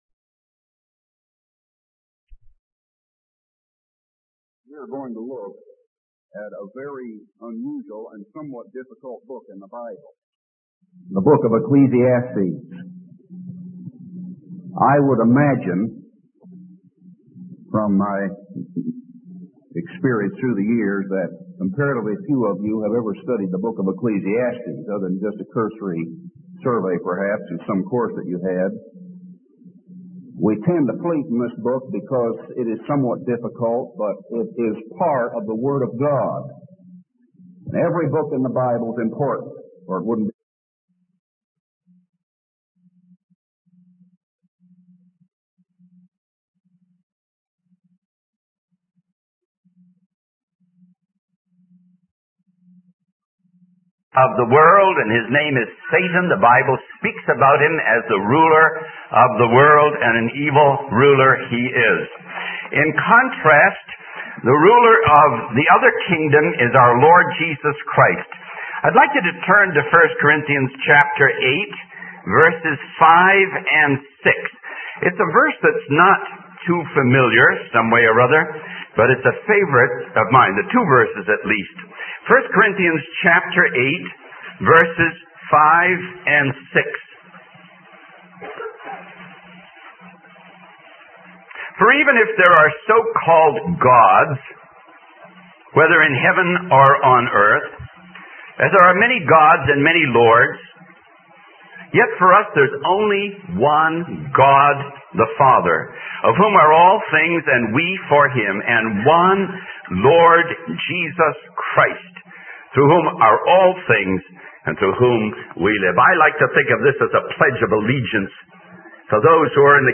The sermon concludes with a prayer for those who have accepted Jesus as their Savior and a reminder of the believer's status as strangers and pilgrims in this world.